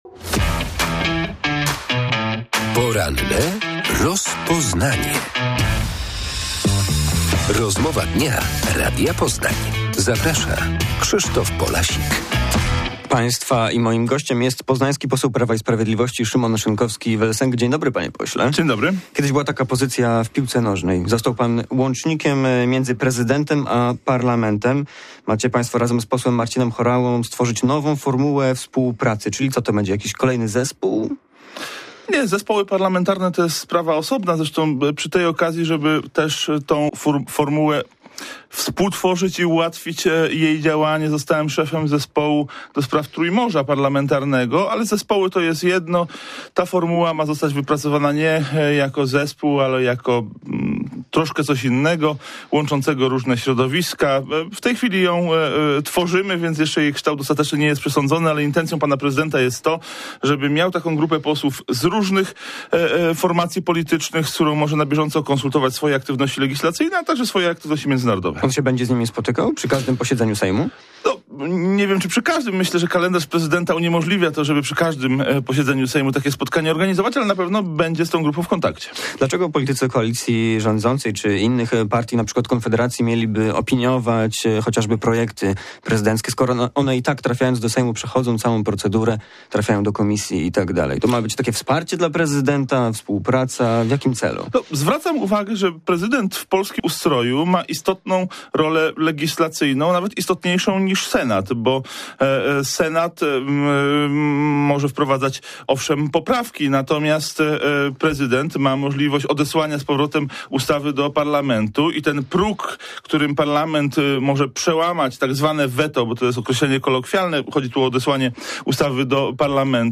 Poseł Prawa i Sprawiedliwości Szymon Szynkowski vel Sęk odpowiada na pytania o współpracę prezydenta Karola Nawrockiego z parlamentem i zaangażowanie głowy państwa w politykę zagraniczną.